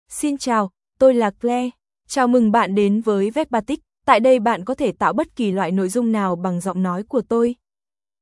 Claire — Female Vietnamese (Vietnam) AI Voice | TTS, Voice Cloning & Video | Verbatik AI
FemaleVietnamese (Vietnam)
Voice sample
Listen to Claire's female Vietnamese voice.
Female